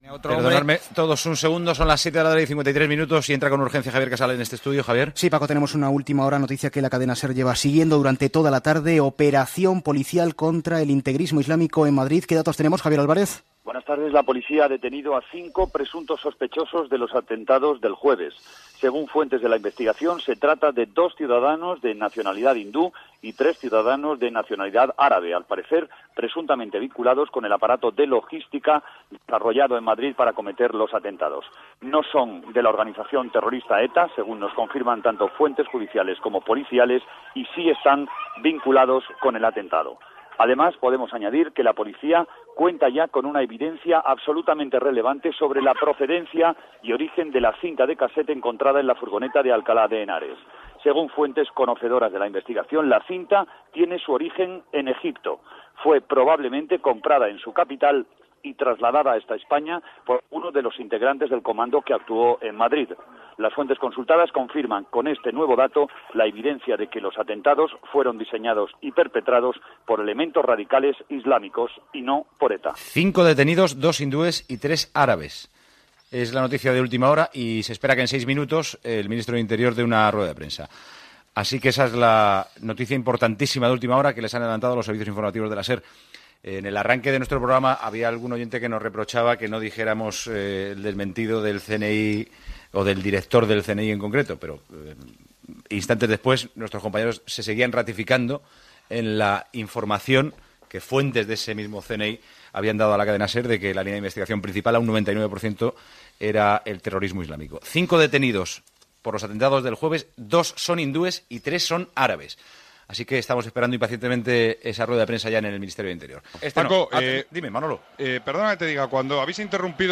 Interrupció del programa per informar que s'ha detingut diverses persones relacionades amb els atemptats de l'11 de març a diversos trens de Madrid, Els autors eren extremistes islàmics i no de la banda ETA
Esportiu